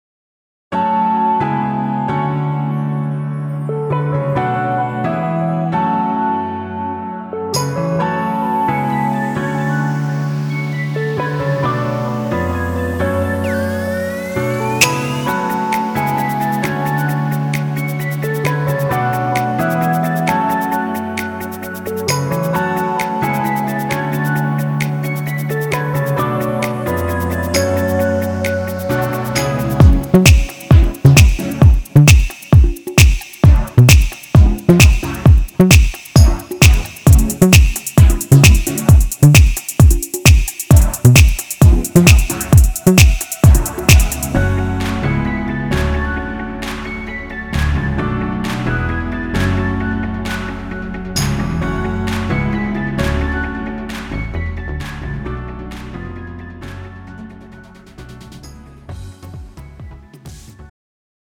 음정 -1키 3:31
장르 가요 구분 Pro MR